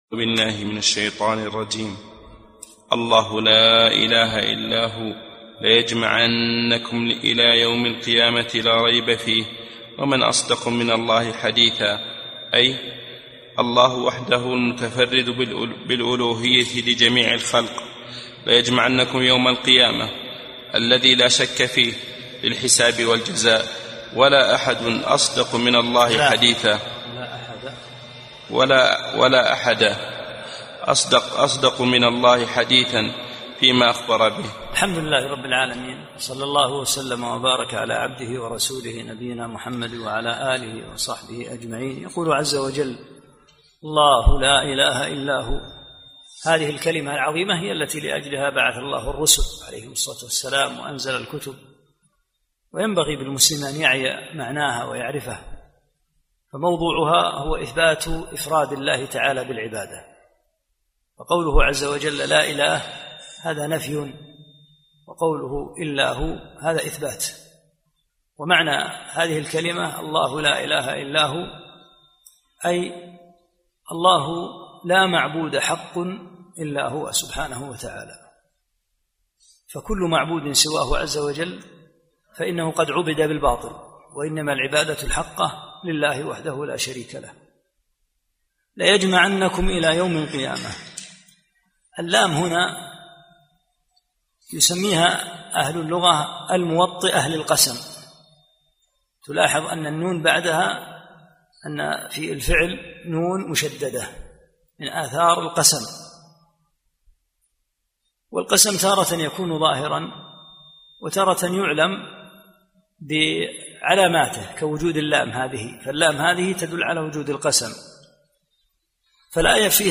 11 - الدرس الحادي عشر